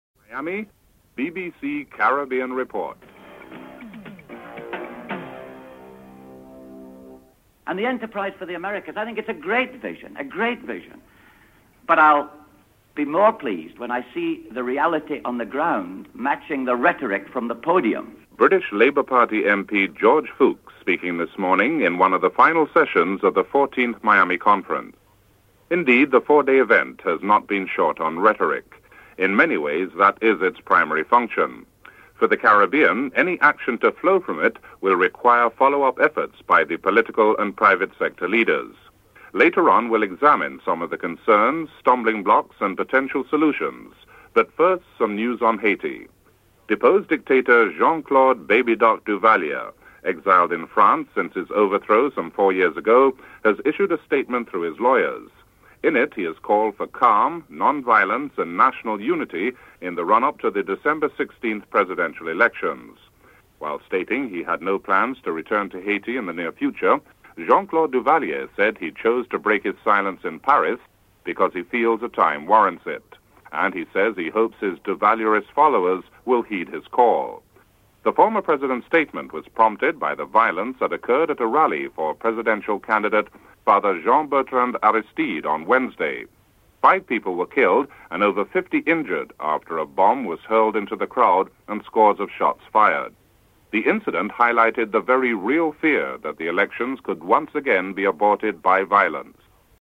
Special edition from the 14th Miami Conference.
1. Headlines (00:00-00:45)